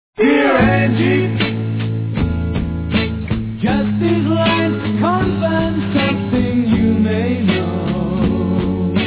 It's a nice ballad.